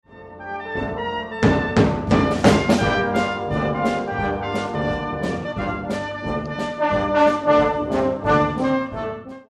Full Brass Band arrangement